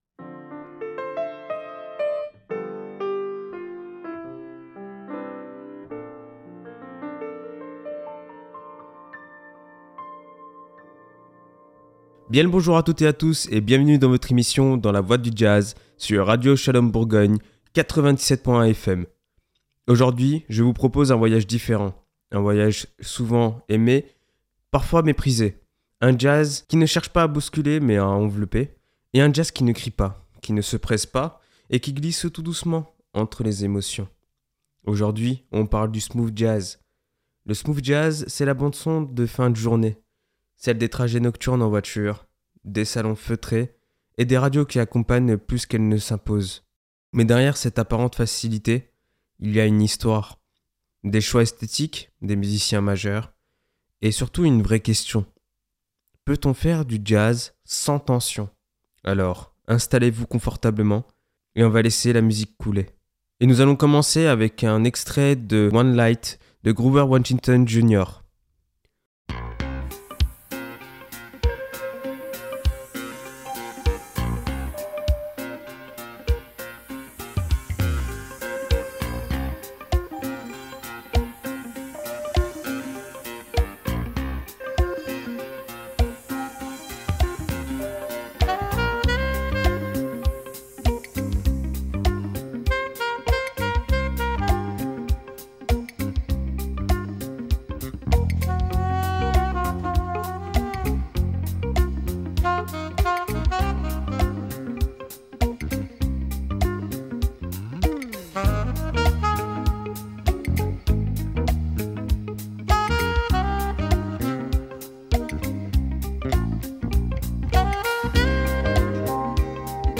Une musique pensée comme un climat, une présence, une bande-son des fins de journée et des trajets nocturnes.
Un jazz qui choisit la douceur comme langage.